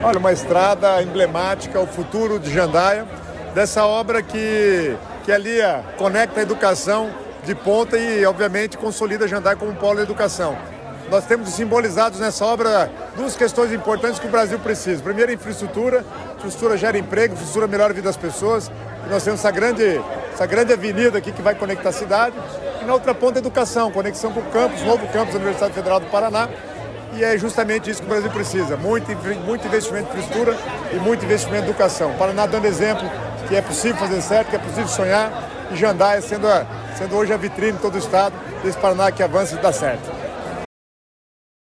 Sonora do secretário das Cidades, Guto Silva, sobre a assinatura da ordem de serviço para construção de sede da UFPR em Jandaia do Sul